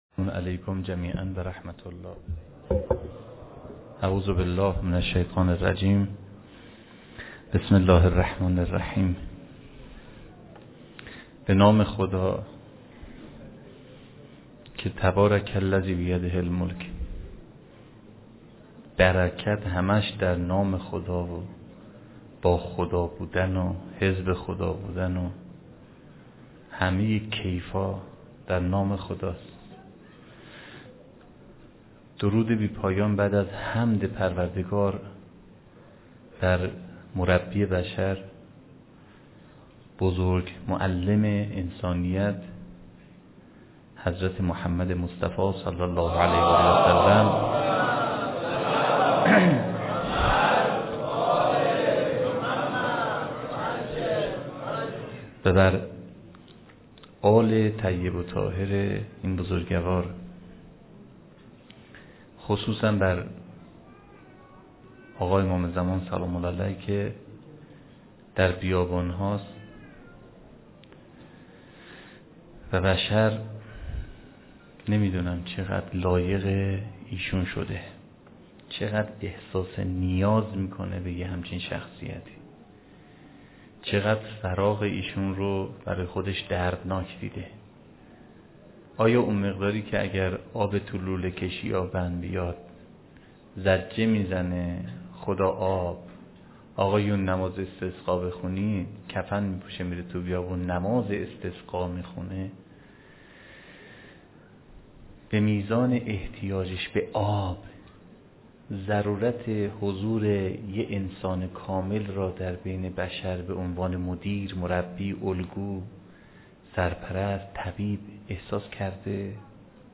سخنرانی
فاطمیه 93 برازجان